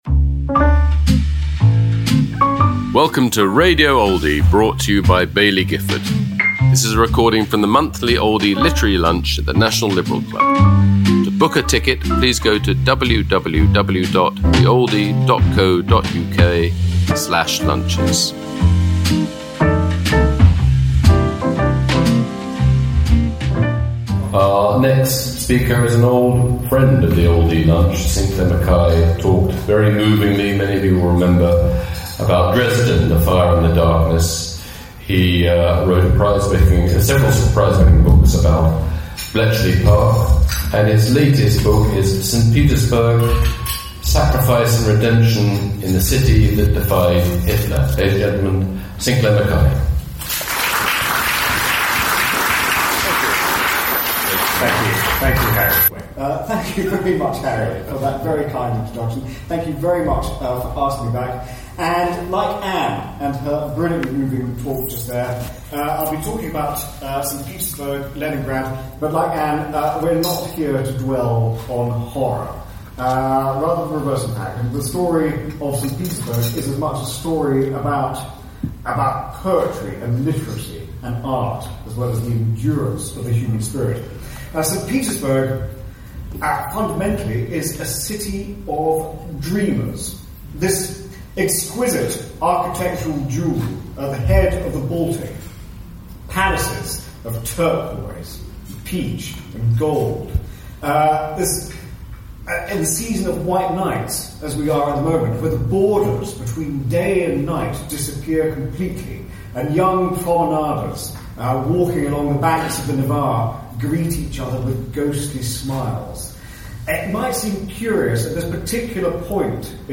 Sinclair McKay speaking about his new book, Saint Petersburg: Sacrifice & Redemption in the City that Defied Hitler, at the Oldie Literary Lunch, held at London’s National Liberal Club, on June 24th 2025.